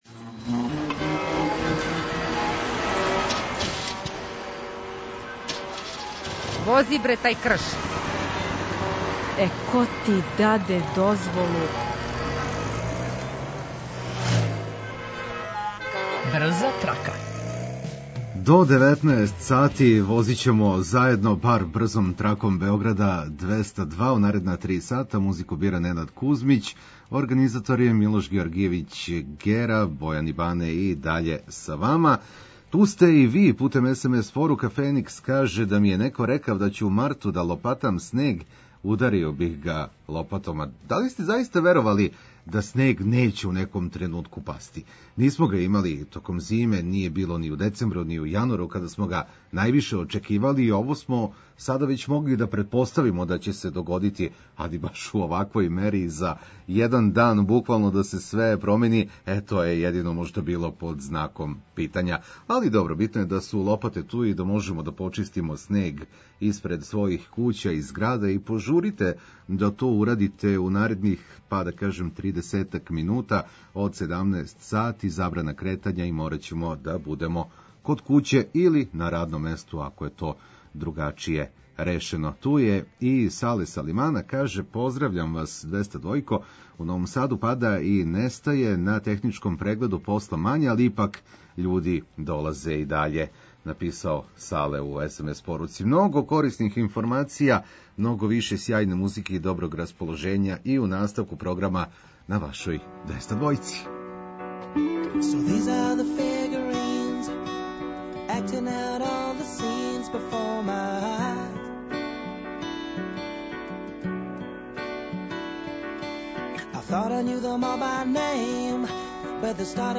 Ту је и одлична музика која ће вам олакшати сваки минут, било где да се налазите.